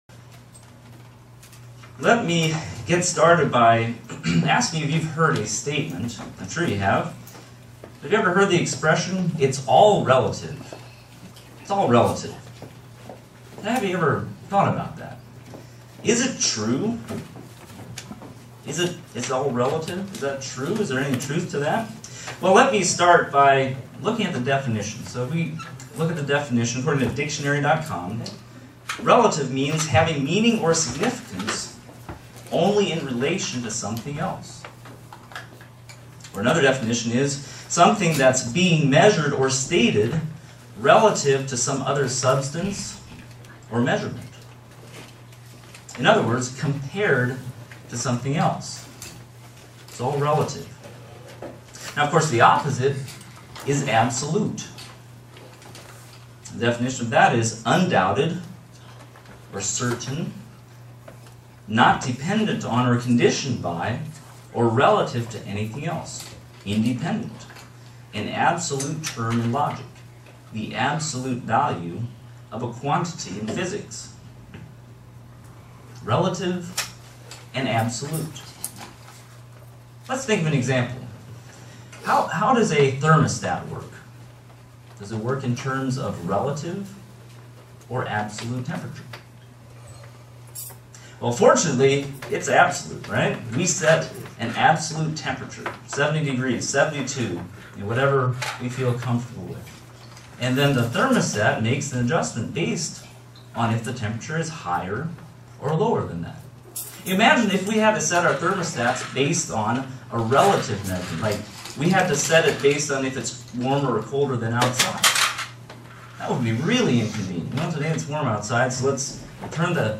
Print How the idea that all things are relative is disproved by the Truth of God. sermon Studying the bible?
Given in Buffalo, NY